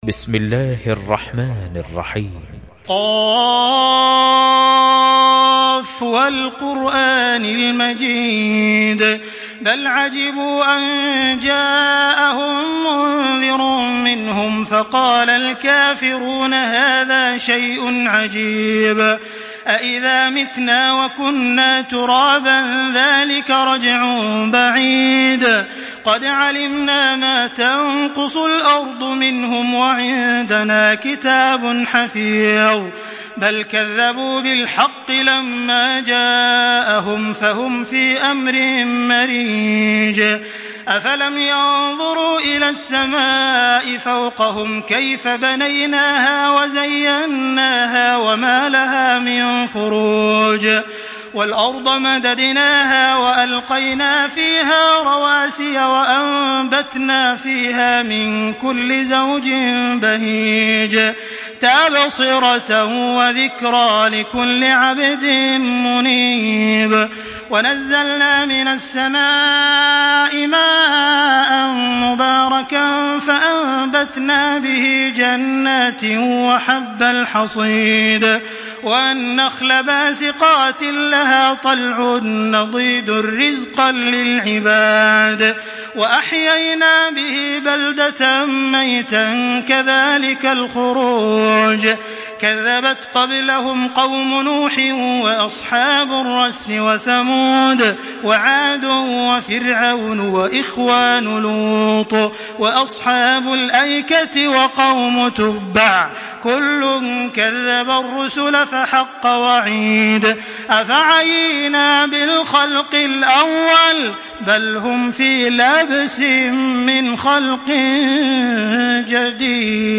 Surah Kaf MP3 by Makkah Taraweeh 1425 in Hafs An Asim narration.
Murattal